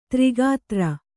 ♪ tri gātra